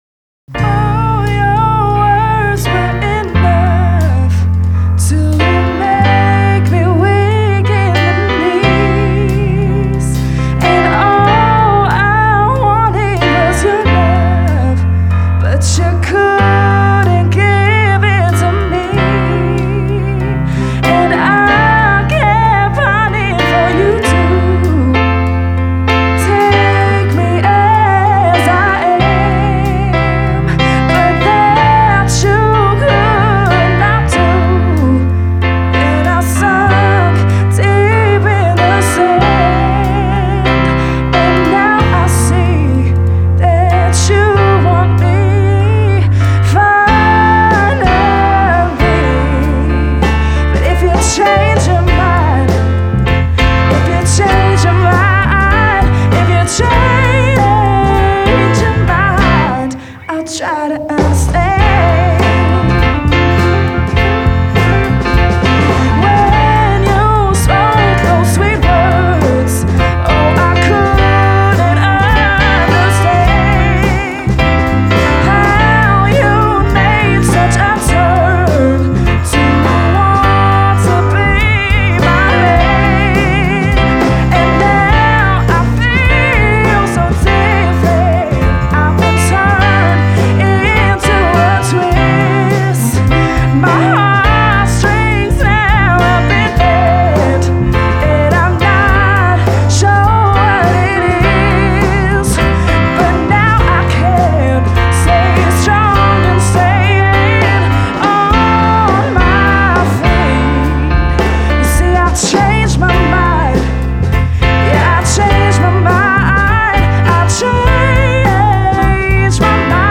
Mastering Samples